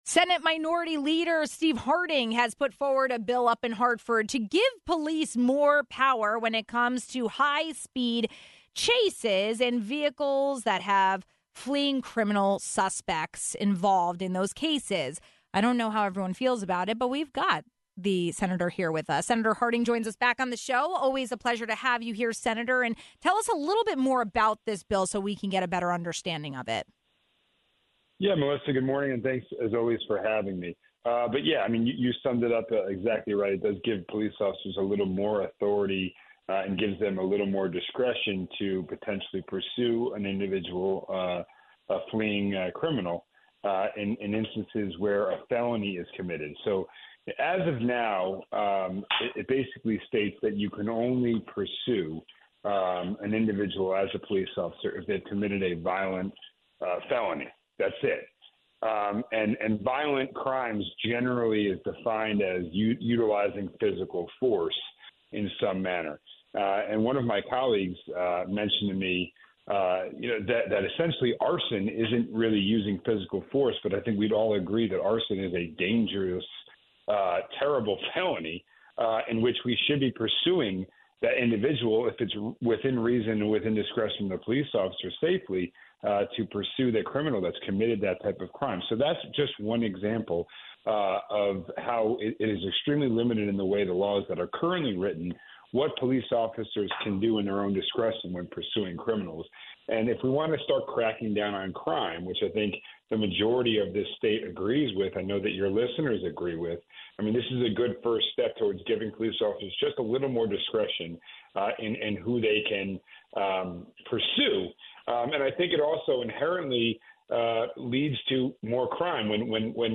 Senate Minority Leader Steve Harding has put forward a bill to give police more power in high-speed chases. Senator Harding explained the bill and issues with the current law blocking police from pursuing criminals.